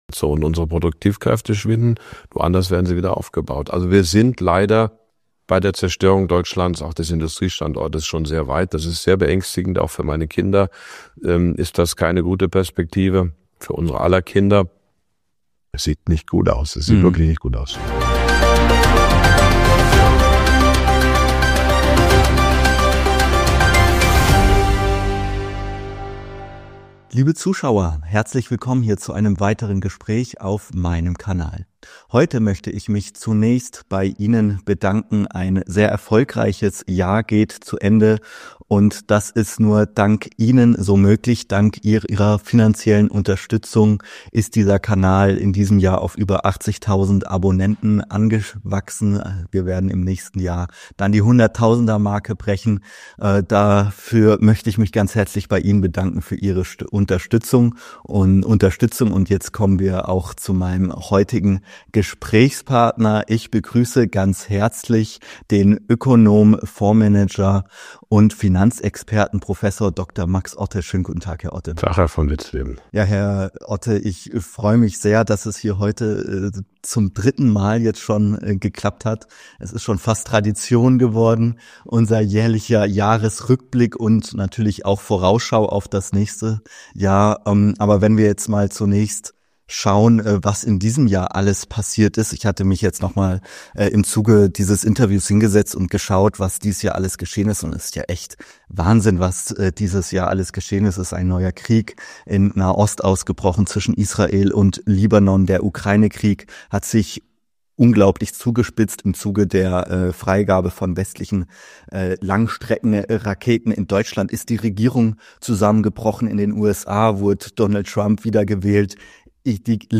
Die beiden diskutieren die deutsche Wirtschaftskrise, geopolitische Veränderungen und die Auswirkungen der US-Präsidentschaftswahl. Ein fundiertes Gespräch über Deutschlands Position in der sich wandelnden Weltordnung.